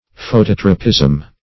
Phototropism \Pho*tot"ro*pism\, n. [Photo- + Gr. ? to turn.]